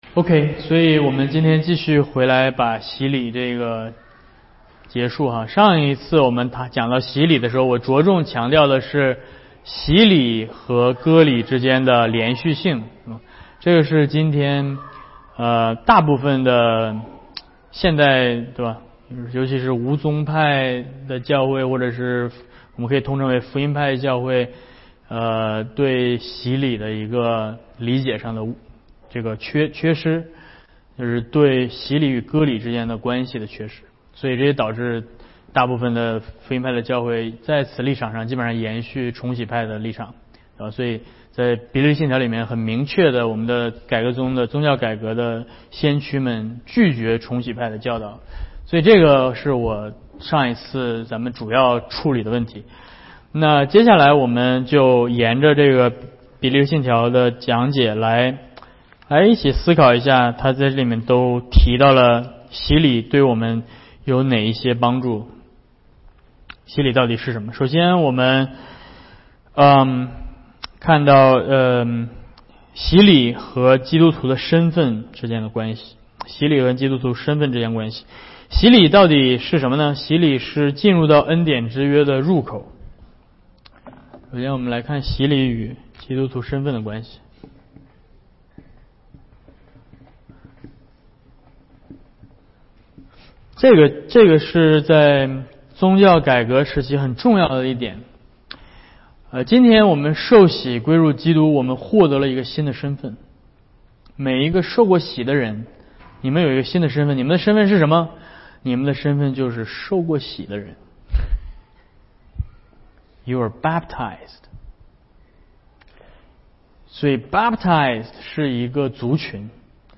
Service Type: 主日学课程